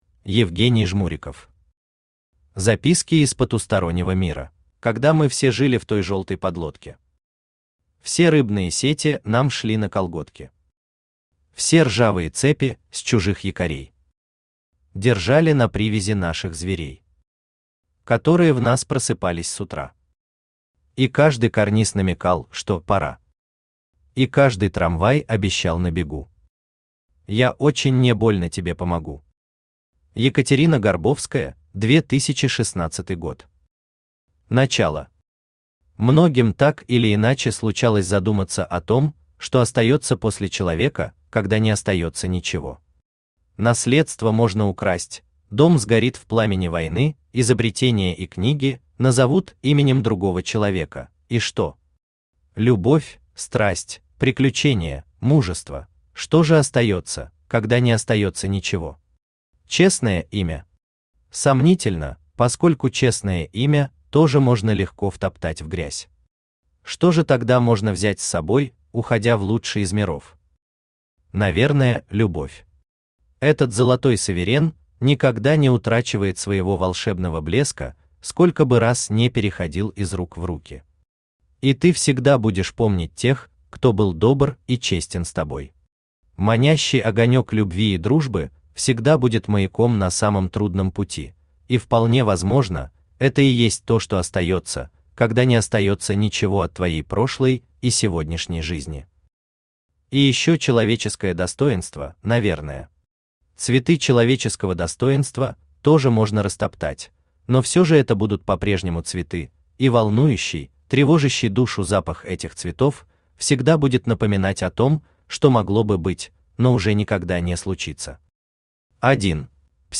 Аудиокнига Записки из потустороннего мира | Библиотека аудиокниг
Aудиокнига Записки из потустороннего мира Автор Евгений Изотович Жмуриков Читает аудиокнигу Авточтец ЛитРес.